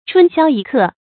春宵一刻 注音： ㄔㄨㄣ ㄒㄧㄠ ㄧ ㄎㄜˋ 讀音讀法： 意思解釋： 歡娛難忘的美好時刻。